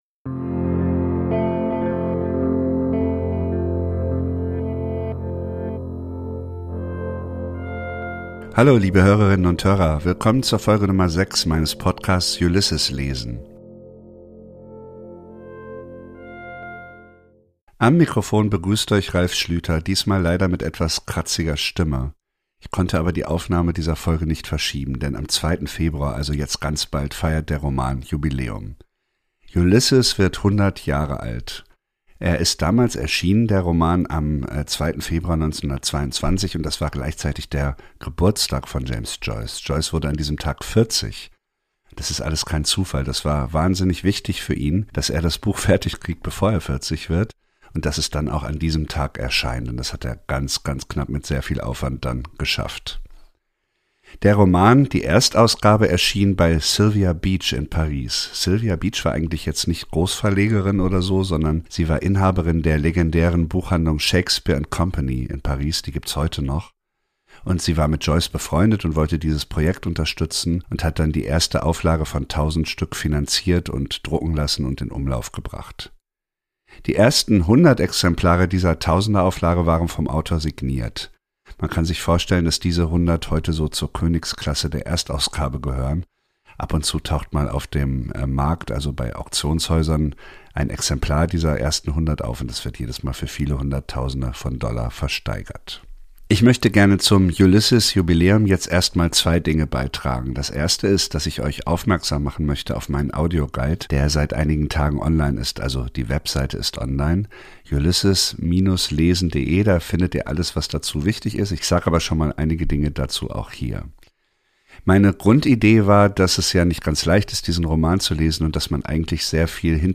»Ulysses« wird 100: Ein Anruf in Dublin ~ »Ulysses« lesen Podcast